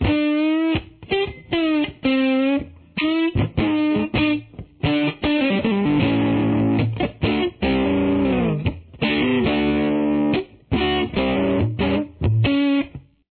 It gives it a percussion-like sound.
Intro Lead Guitar